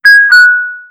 oscarpilot/selfdrive/frogpilot/assets/custom_themes/tesla_theme/sounds/disengage.wav
disengage.wav